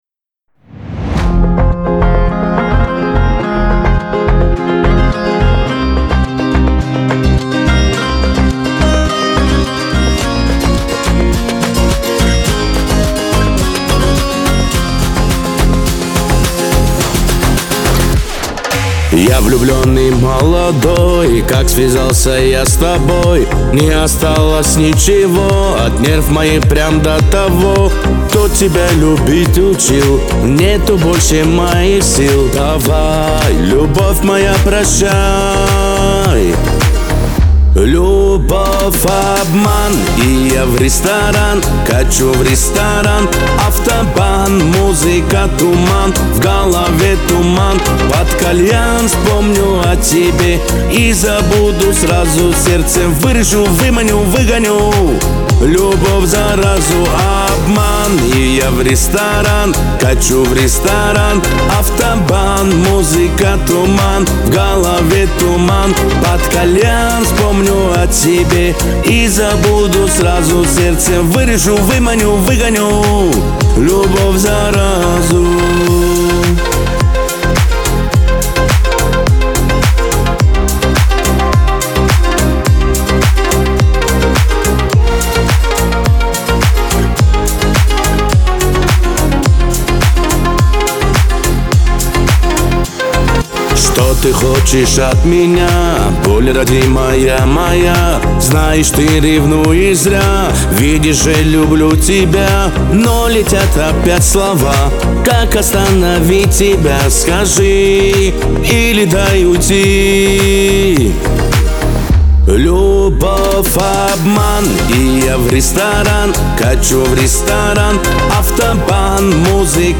Кавказ – поп
Лирика